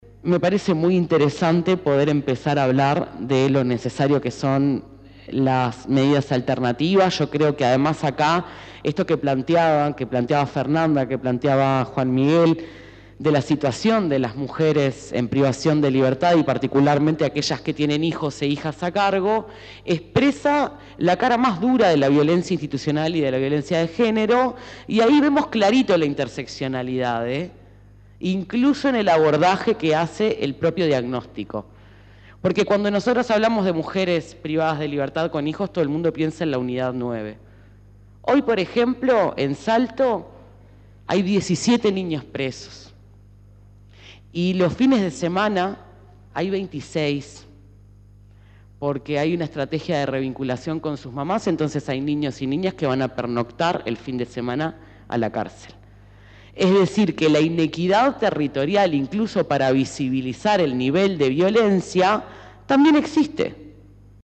En la presentación de este informe, hicieron uso de la palabra diferentes actores políticos, tanto del gobierno como legisladores del oficialismo y de la oposición.